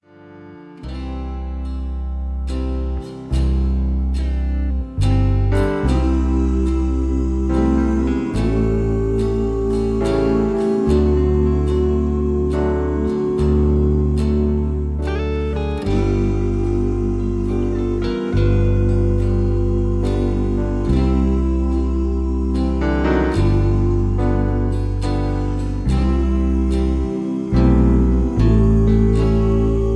easy litstening
country music